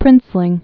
(prĭnslĭng)